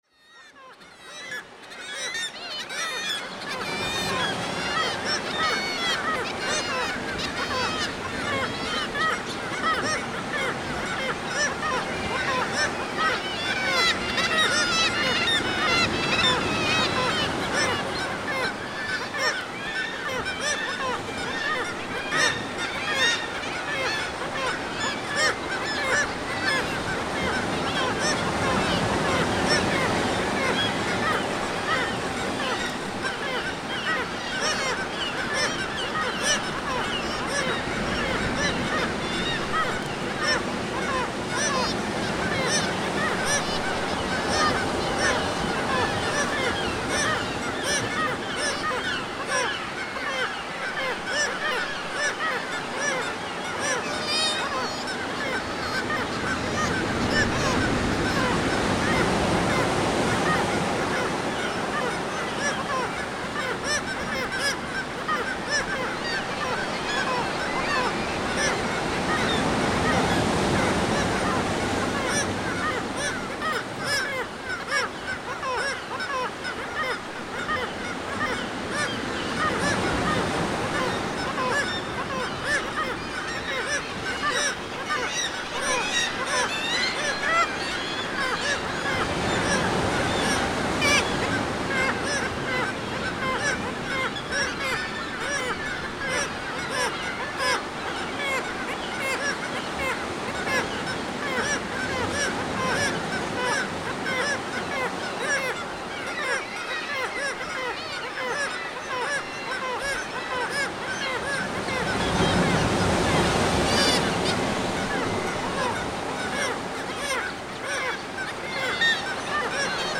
So what ever about the quality of this recording is, it can be valuable in the future.